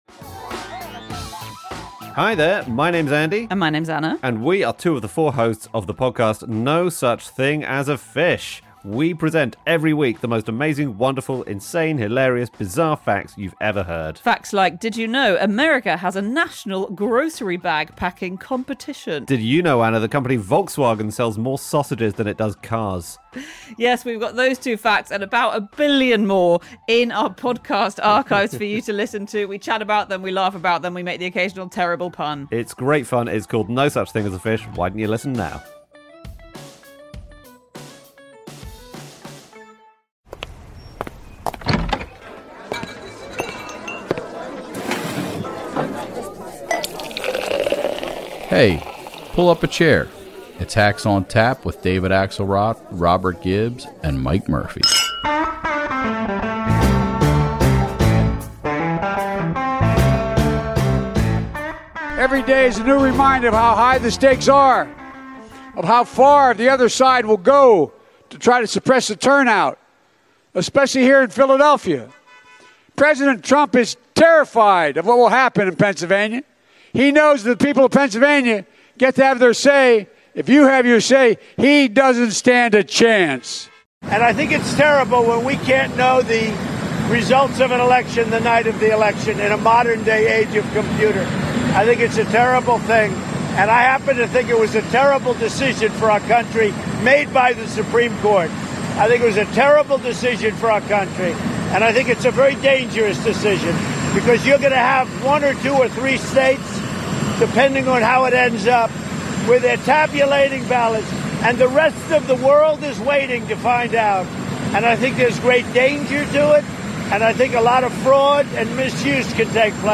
Axe, Murphy, and Gibbs sit down for the last time before Election Day to discuss the final moments of the campaign, what to expect on November 3rd, and when we might have an answer on who will be the next President of the United States.